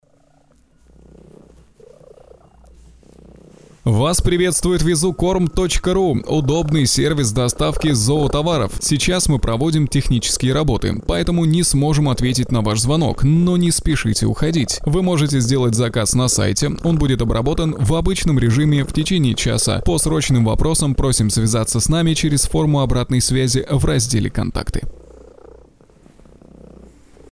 Пример звучания голоса
Муж, Автоответчик/Средний
Beyerdynamic Fox/Grace Design m101/MacBook